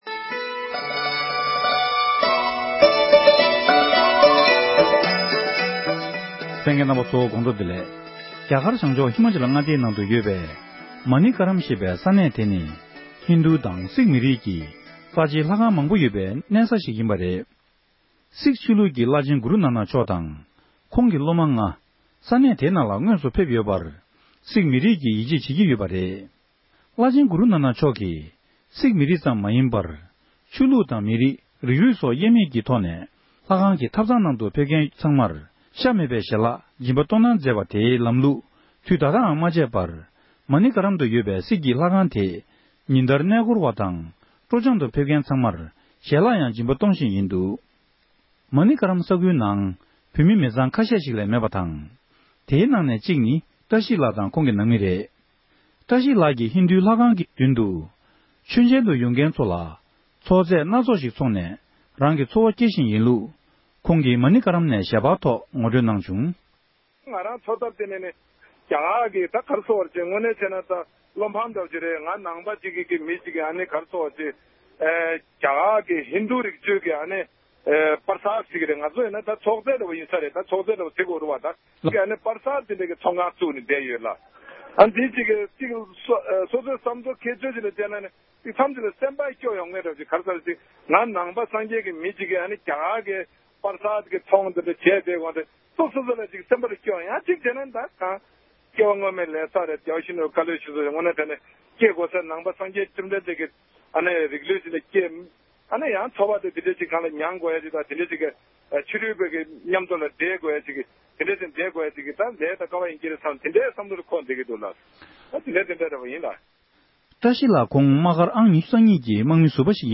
བཀའ་འདྲི་ཞུས་པར་གསན་རོགས༎